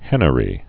(hĕnə-rē)